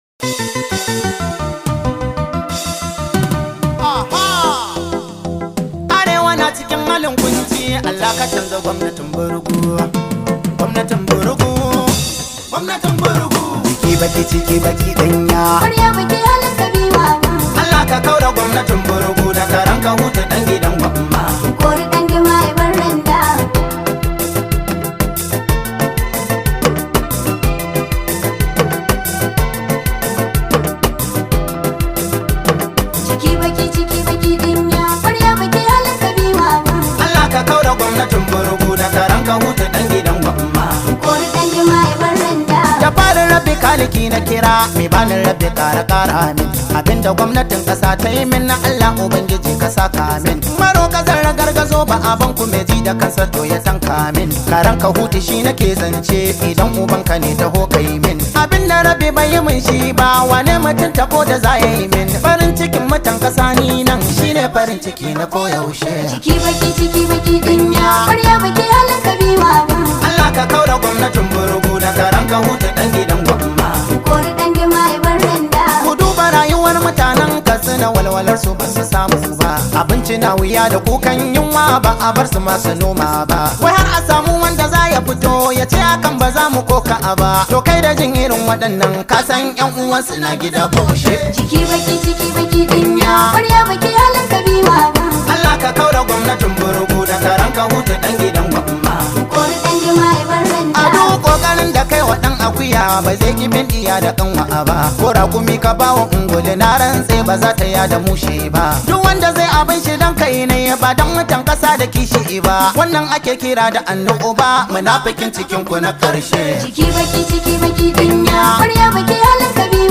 Hausa Singer